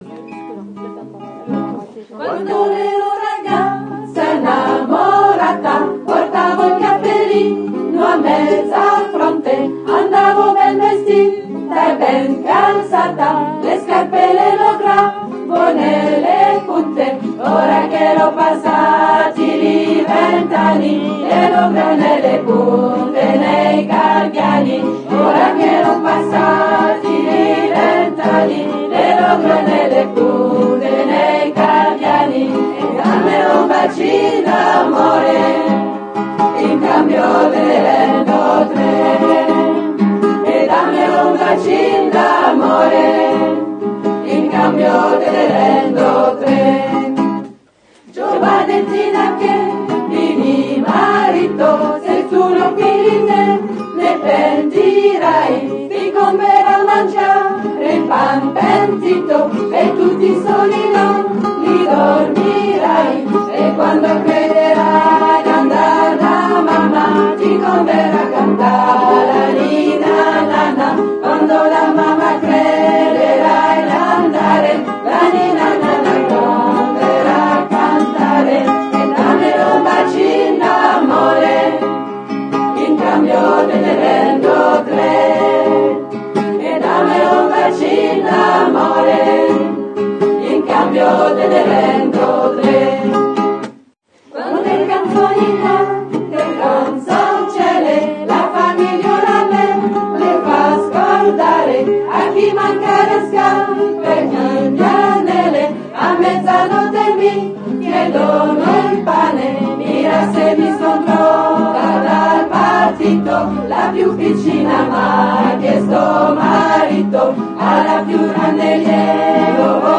Pan_Pentito_Chorale.mp3